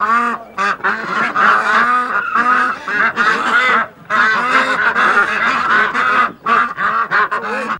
Geese Annoying Angry